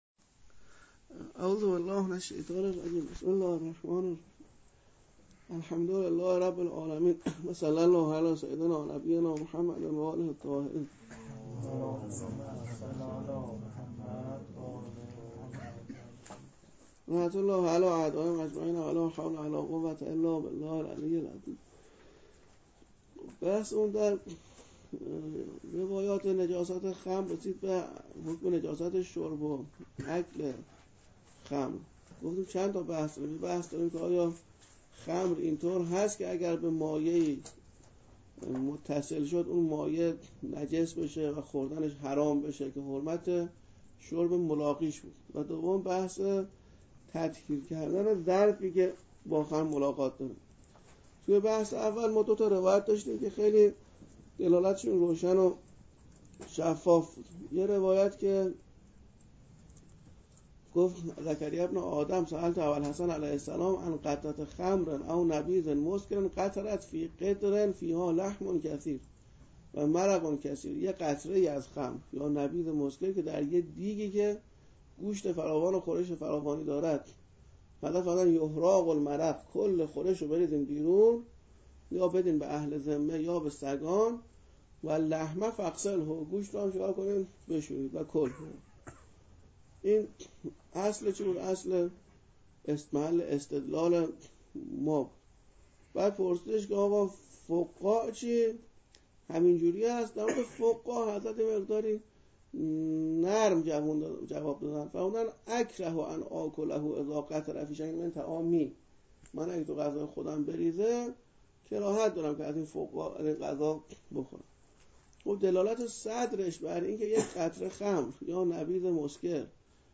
درس خارج فقه، تنجبیس متنجس، جلسه چهارم